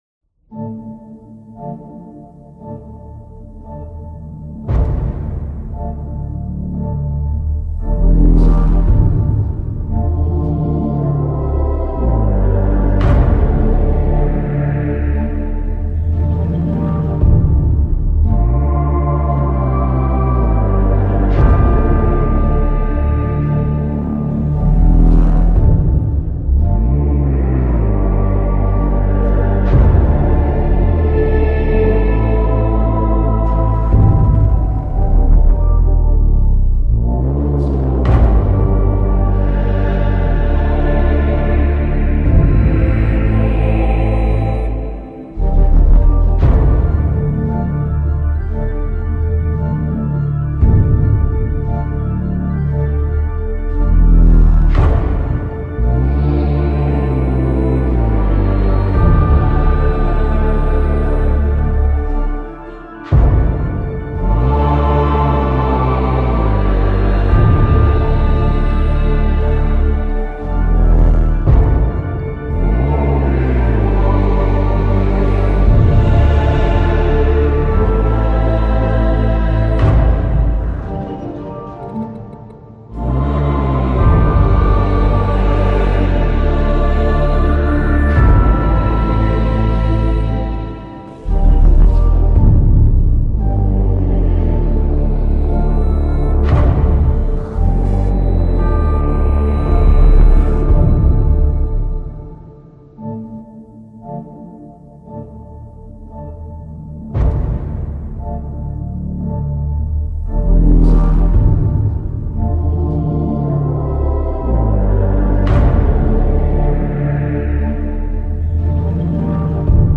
chant.mp3